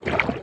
Sfx_creature_spikeytrap_munch_03.ogg